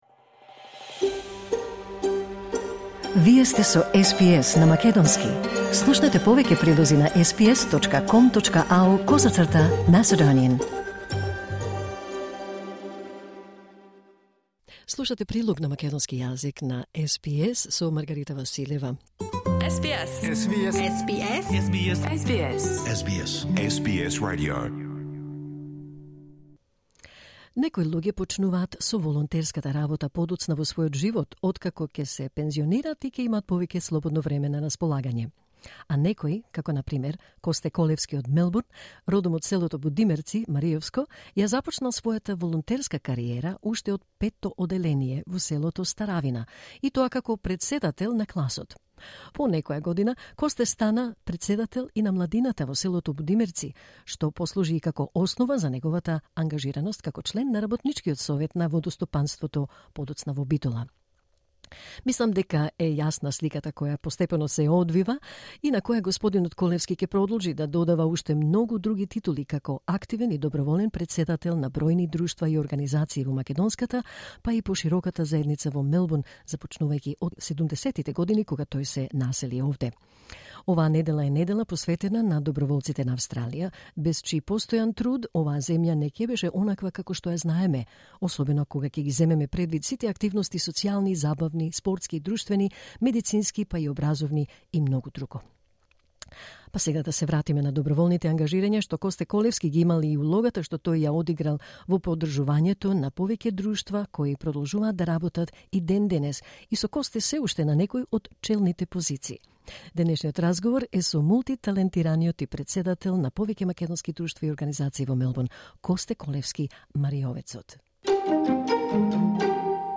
SBS Macedonian Program Live on Air 18 May 2023